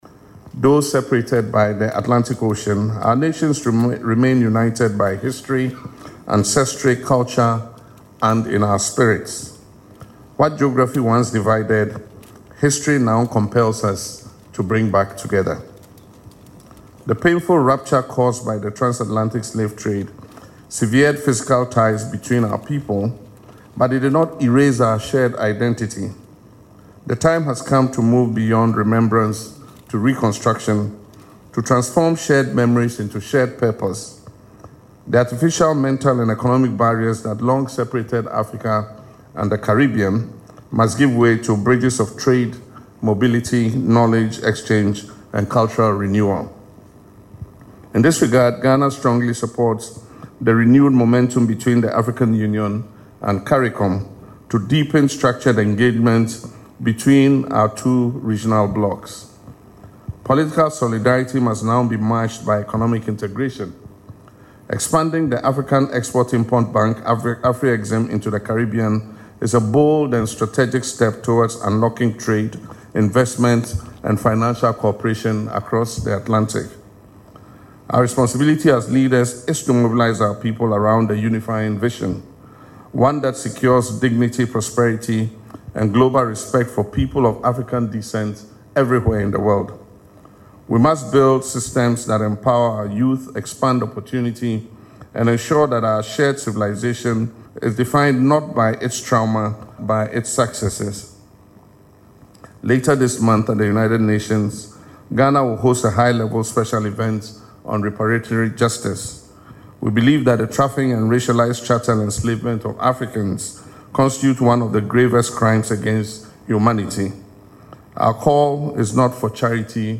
President Mahama made the remarks at an Official State Luncheon held in honour of the Prime Minister of Saint Kitts and Nevis, Terrance Michael Drew, who is in Ghana on a state visit.
LISTEN TO PRESIDENT MAHAMA IN THE AUDIO BELOW: